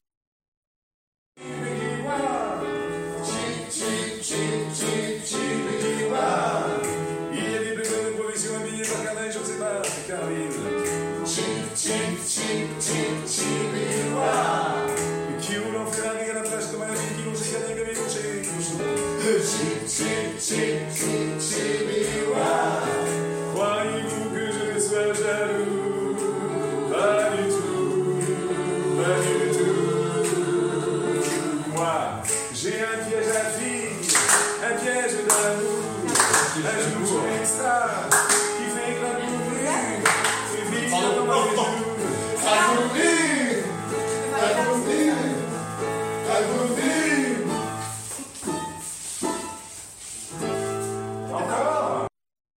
LIVE (les répétitions)